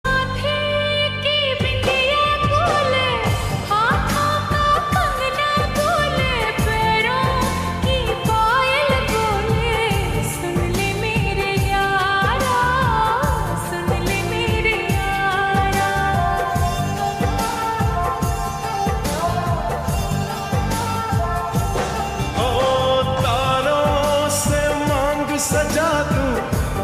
Lofi Ringtones